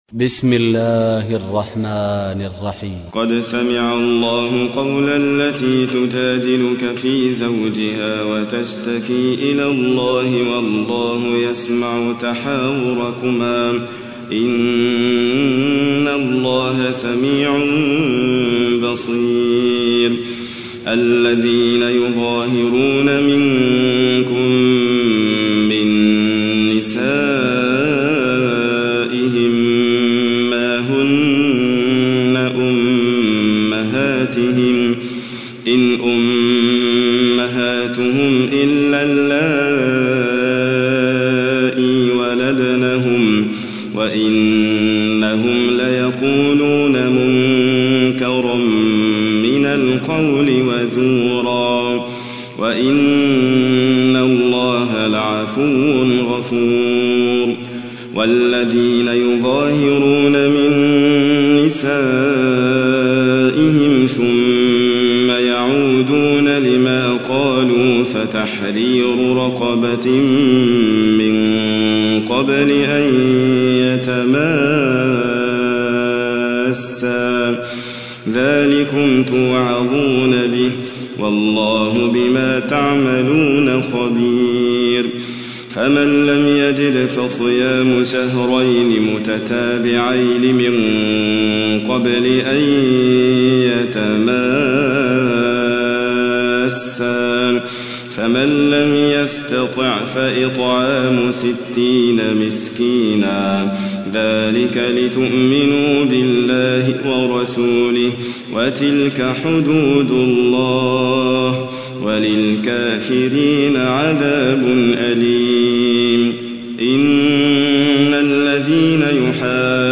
تحميل : 58. سورة المجادلة / القارئ حاتم فريد الواعر / القرآن الكريم / موقع يا حسين